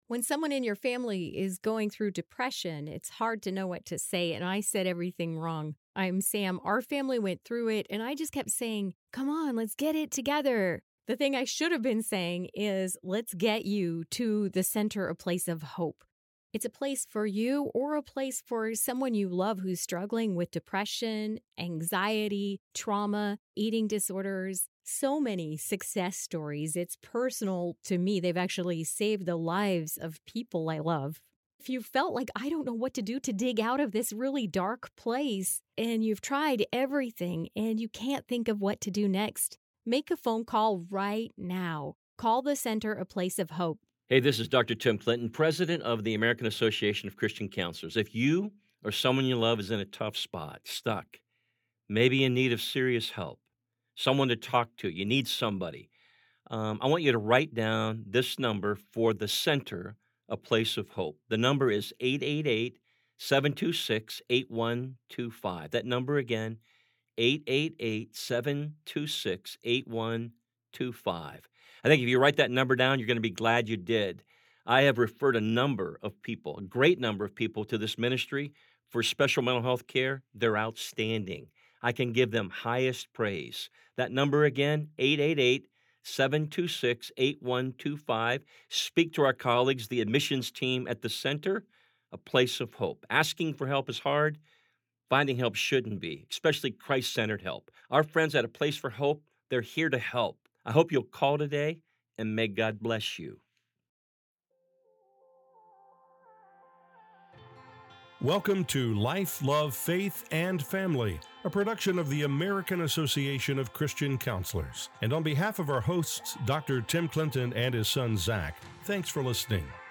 at this year’s National Religious Broadcasters Convention in Texas